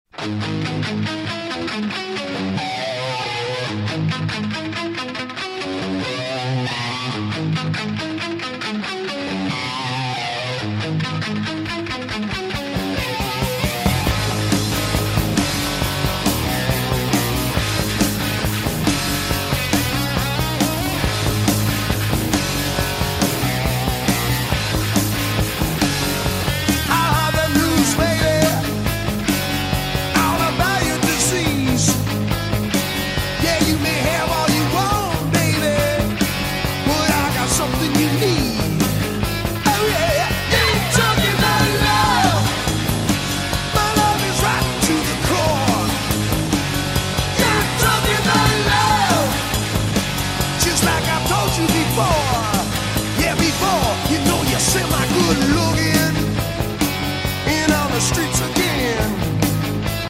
Driving The V6 Ford Mustang Sound Effects Free Download